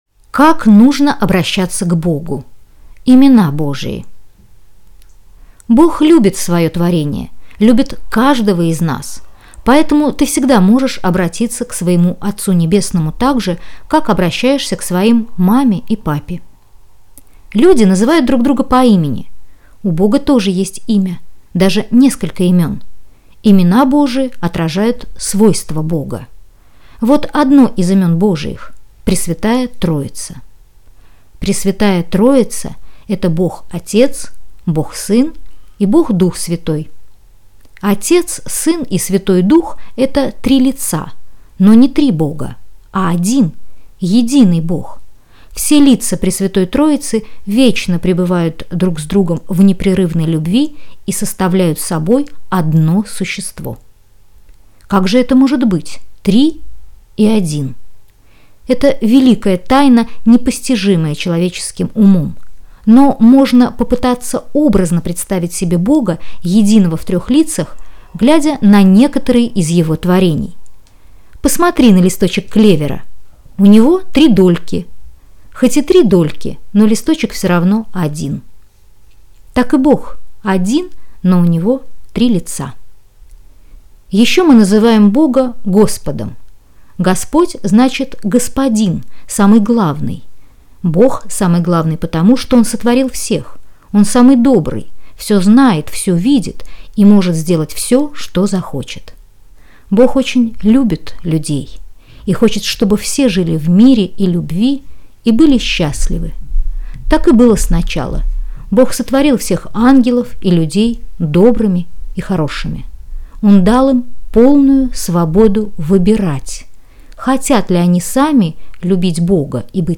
(Аудио сборник "Детский молитвослов с комментариями")